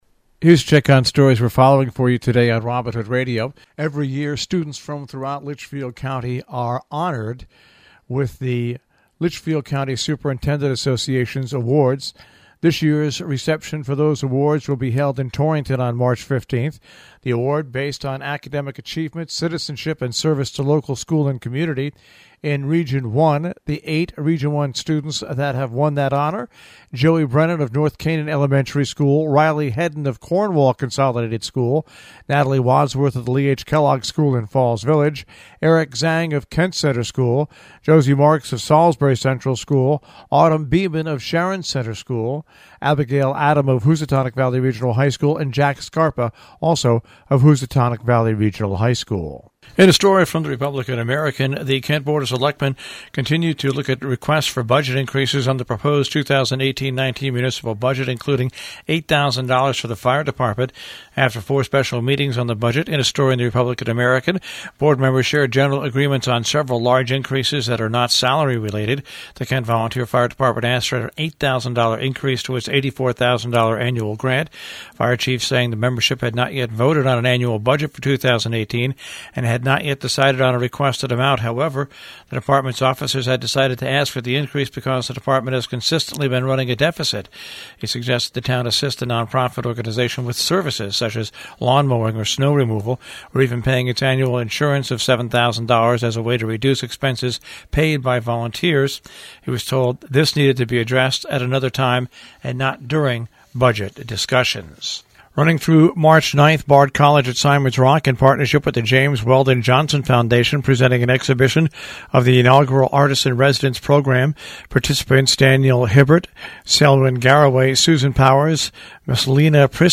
WHDD-BREAKFAST CLUB NEWS WEDNESDAY FEBRUARY 28.mp3